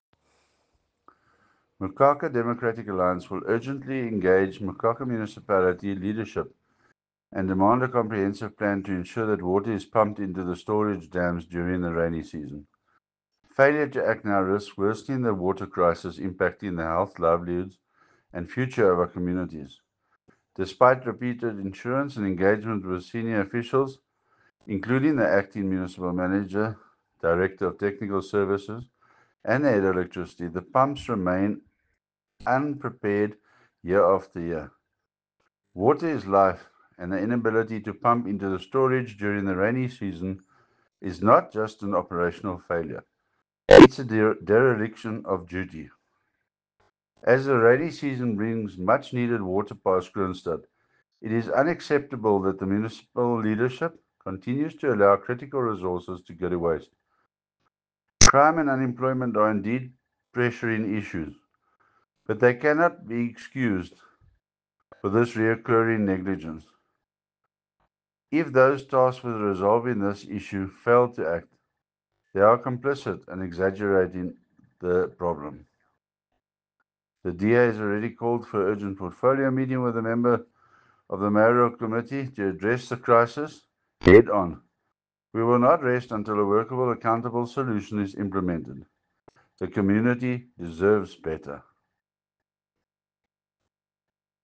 English soundbite by Cllr Chris Dalton,